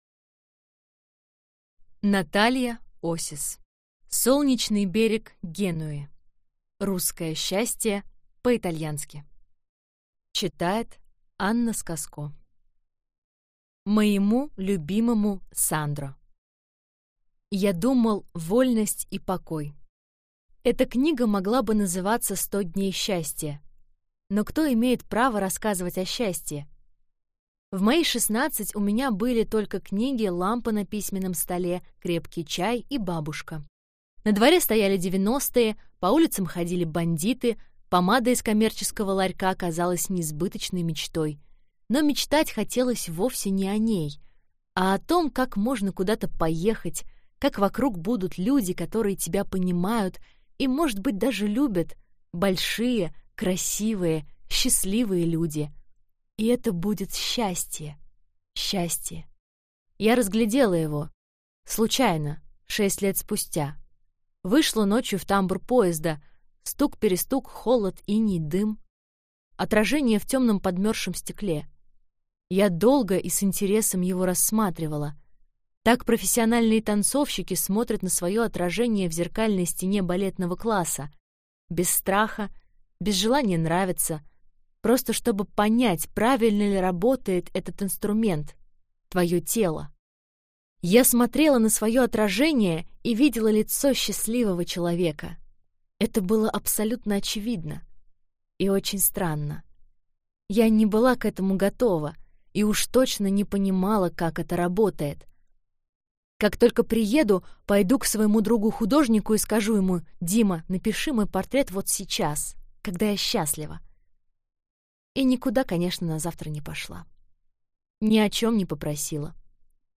Аудиокнига Солнечный берег Генуи. Русское счастье по-итальянски | Библиотека аудиокниг